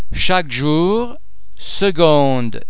| the [q] and [c] under the influence of [j] and [n] is pronounced [g]